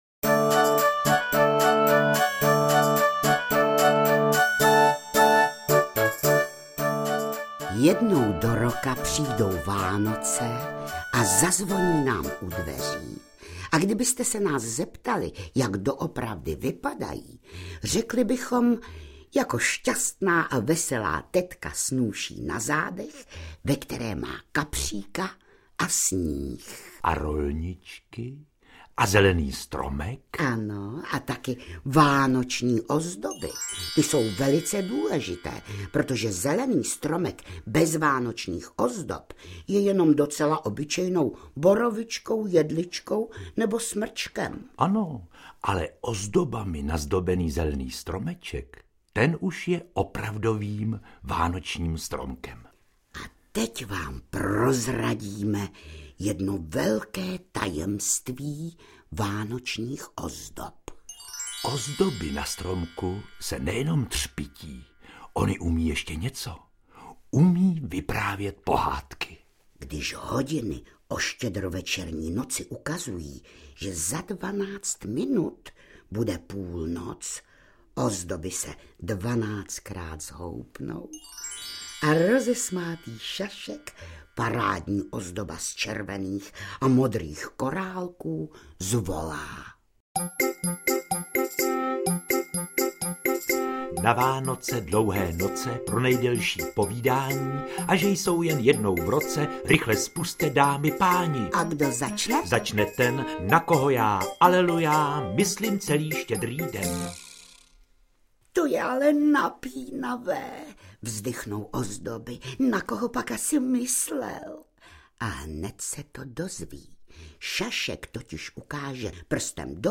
Interpreti:  Jiřina Bohdalová, Josef Dvořák
AudioKniha ke stažení, 4 x mp3, délka 1 hod. 8 min., velikost 61,6 MB, česky